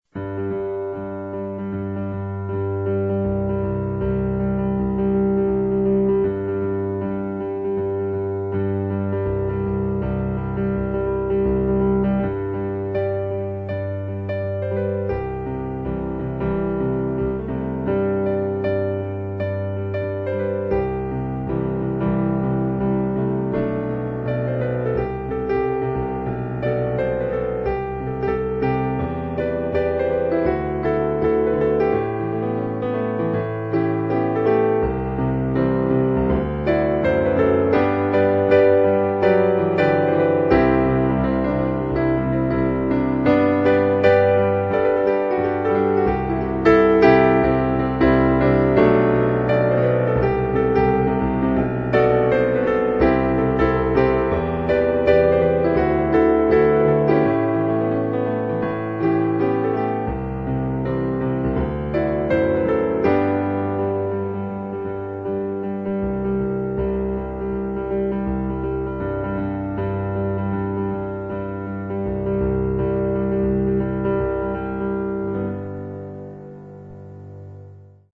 The first lead phrase is similar to the ending song of the movie, and that's not a coincidence... but my intent was to reproduce my feeling from the movie rather than the music itself.